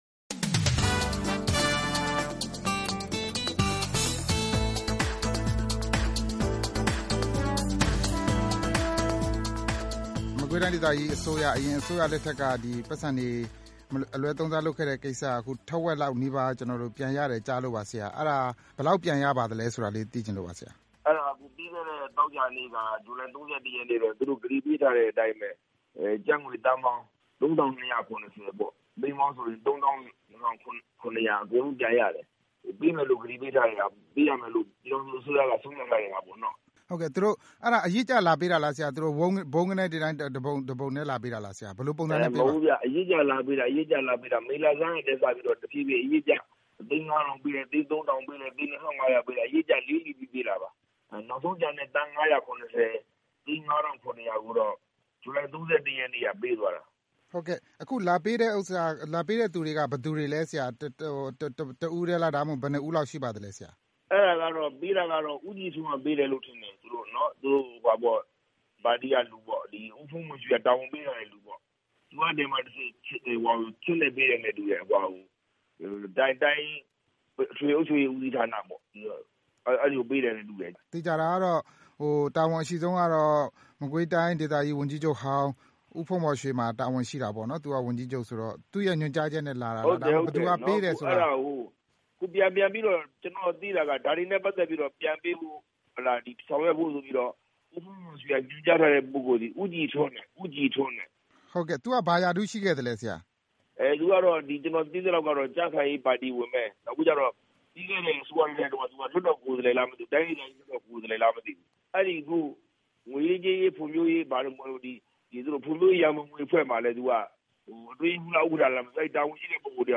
ဒီအကြောင်း မကွေးတိုင်းဒေသကြီး ဝန်ကြီးချုပ် ဒေါက်တာအောင်မိုးညိုကို ဆက်သွယ် မေးမြန်းပြီး